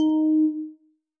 Techmino/media/sample/bell/7.ogg at 59cd9347c2e930d93b419b9637e1822f2d5d8718